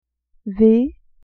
v vay